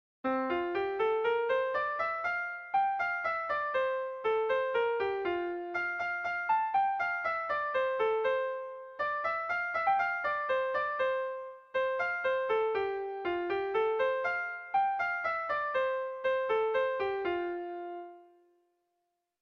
Kontakizunezkoa
Arpegioz behetik gorako hasera hau ez da hain arraroa gure doinuetan
A1BA2